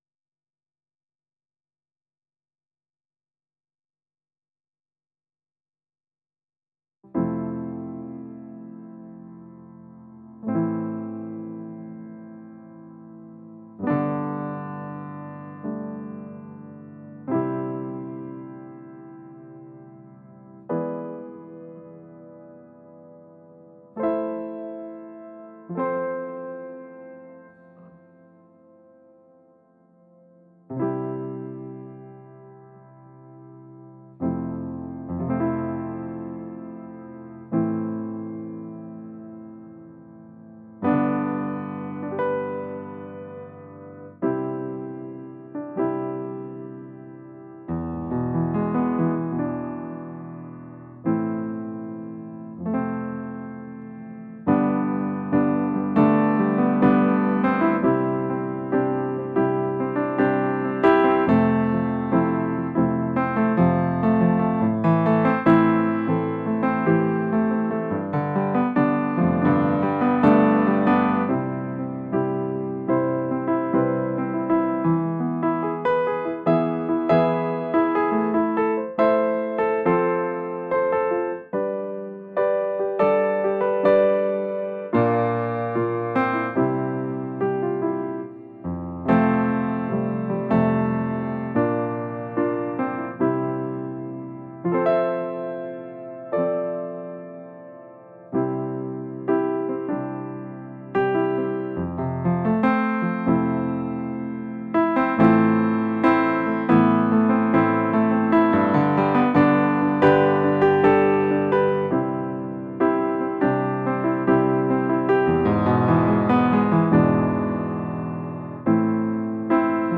piano.wav